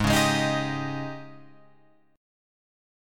G#M7sus4 Chord